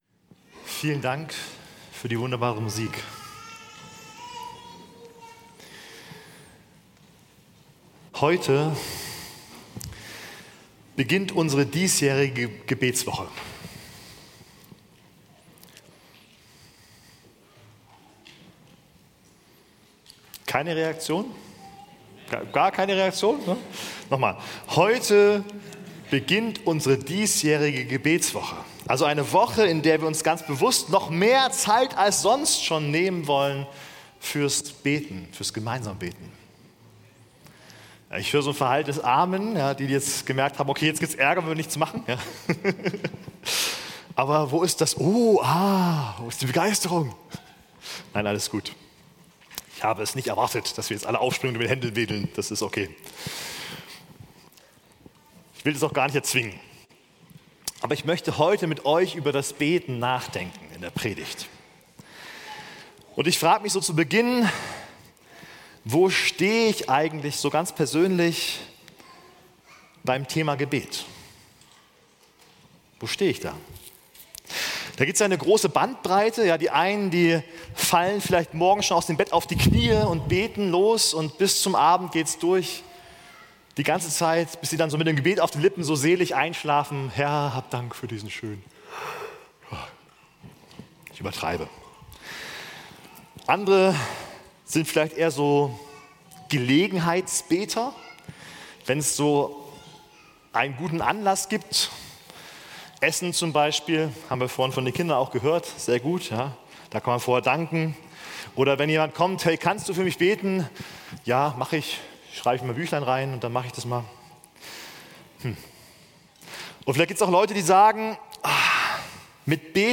Mitschnitt vom 22.11.2025 zum Thema „Gebetswoche"